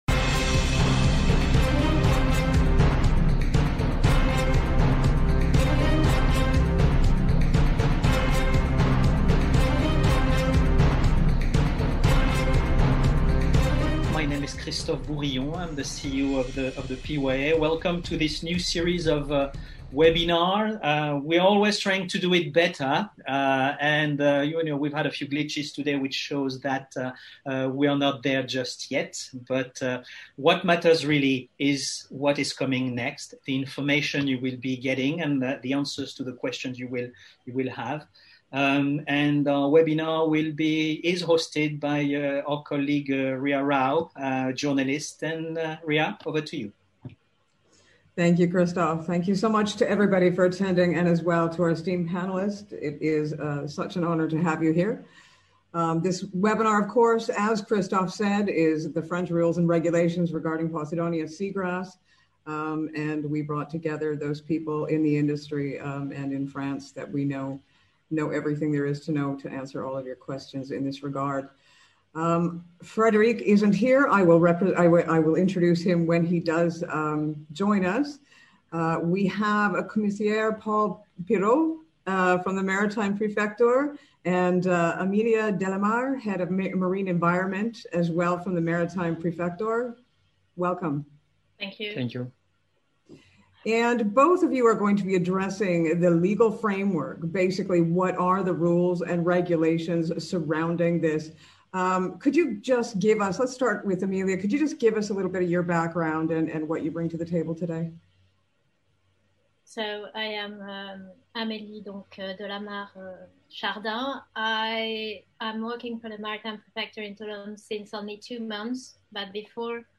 PYA WEBINAR French Rules and Regulations regarding Posidonia Seagrass